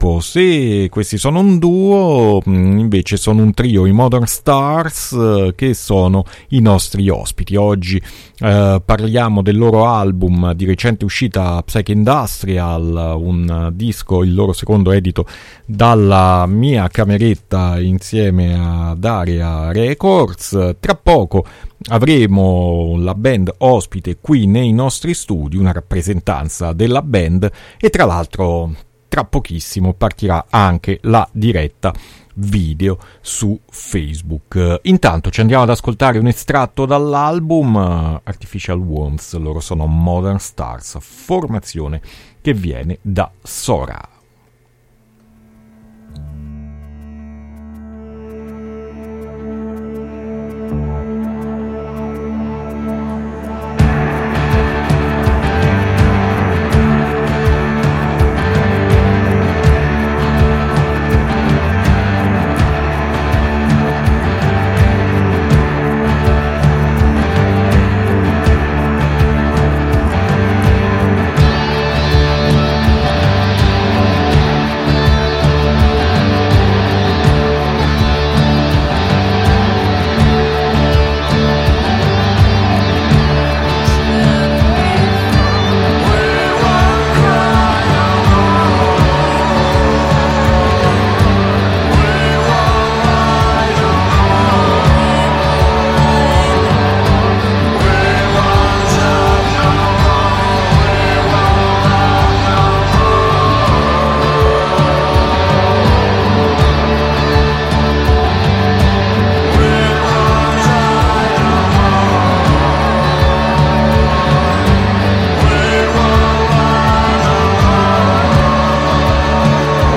La band è stata ospite in studio